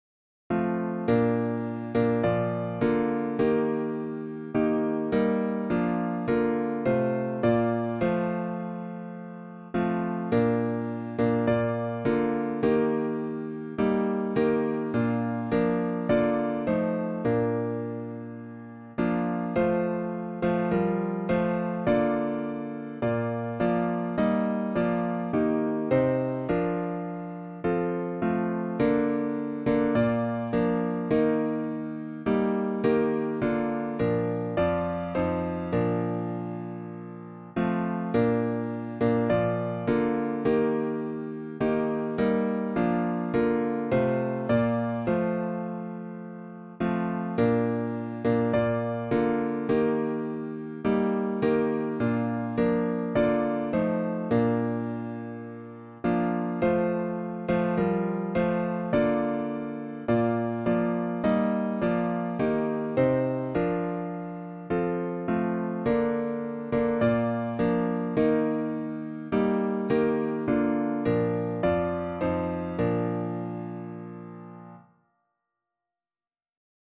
for piano